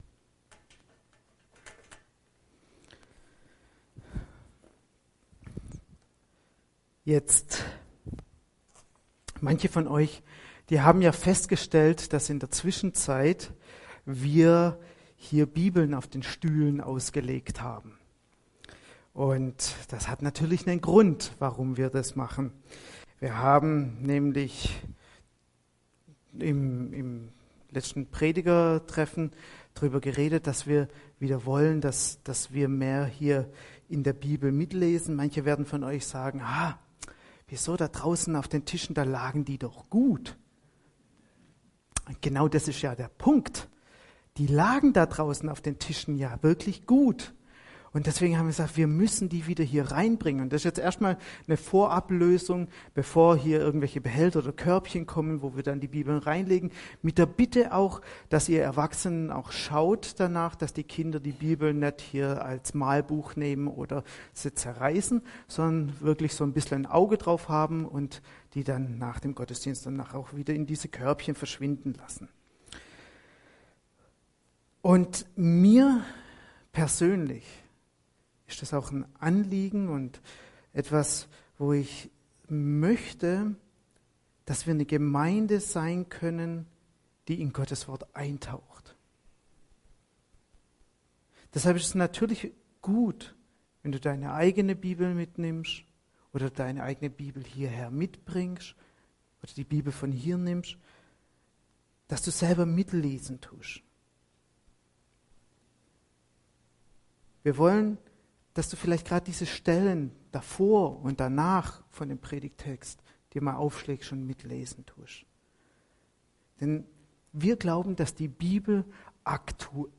Predigt vom 28.